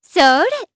Shamilla-Vox_Attack1_jp.wav